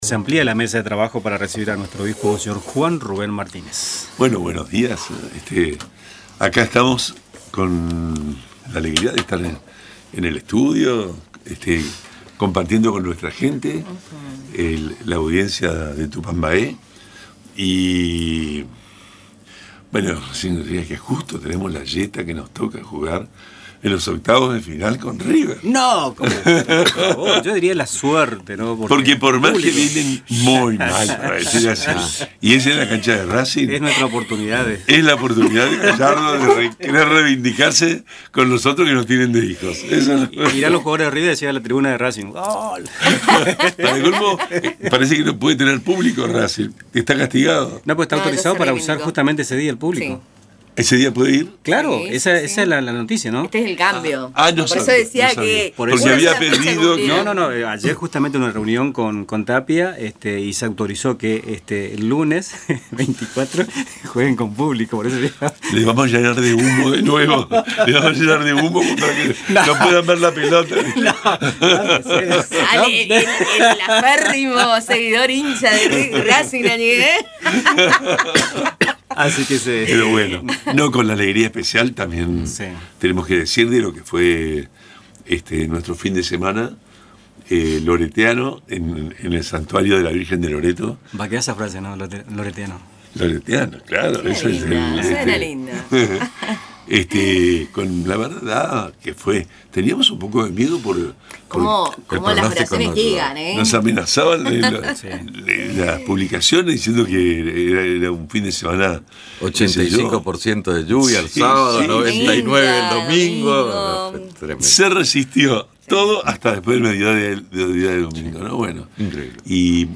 Durante su visita a los estudios de Radio Tupambaé, el obispo de Posadas, Mons. Juan Rubén Martínez, compartió sus impresiones sobre la reciente peregrinación al santuario de la Virgen de Loreto, hizo fuertes comentarios sobre los cobros realizados por Vialidad Nacional y reflexionó sobre la importancia de la devoción mariana en la fe católica.